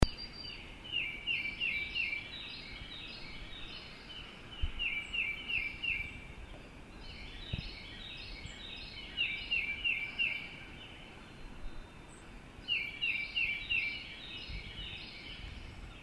sing